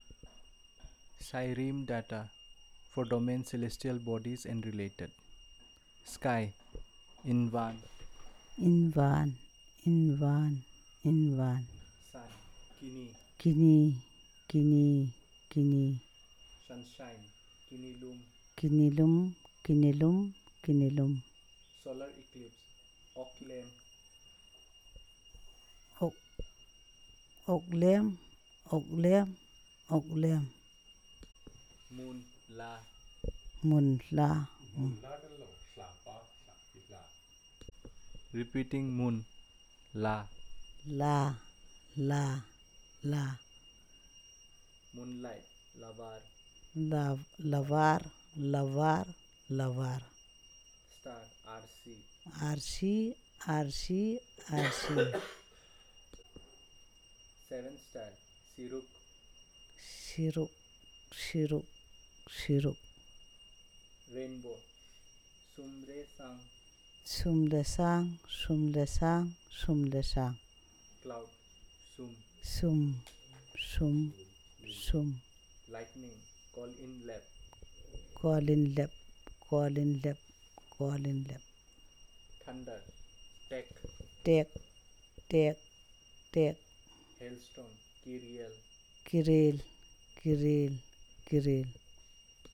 Elicitation of words about celestial bodies and related